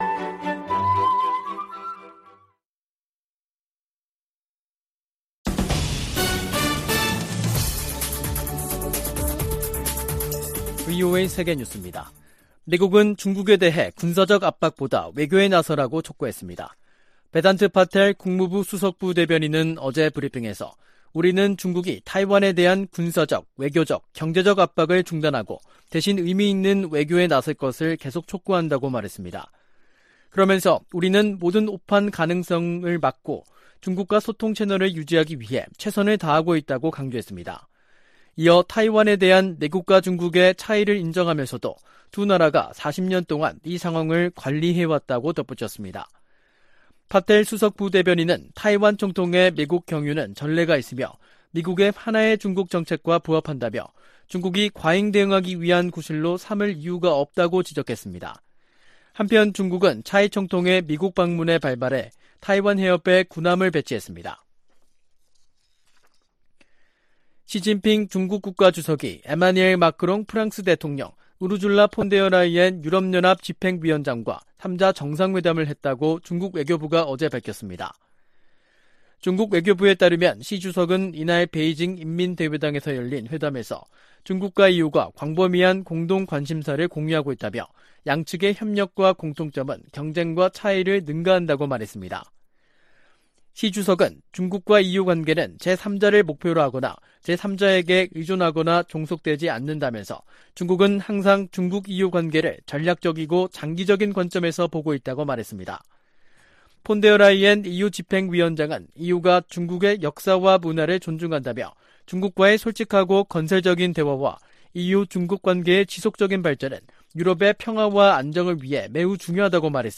VOA 한국어 간판 뉴스 프로그램 '뉴스 투데이', 2023년 4월 7일 2부 방송입니다. 미한일 북핵 수석대표들이 7일 서울에서 공동성명을 발표하고 유엔 회원국들에 안보리 결의를 완전히 이행할 것을 촉구했습니다. 백악관은 북한이 대화에 복귀하도록 중국이 영향력을 발휘할 것을 촉구했습니다. 북한 정보기술 노동자와 화가 등이 여전히 중국과 동남아시아에서 외화벌이를 하고 있다고 유엔이 지적했습니다.